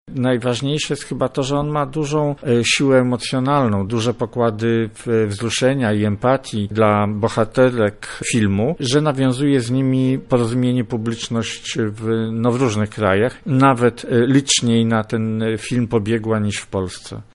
Sukces polskiego filmu komentuje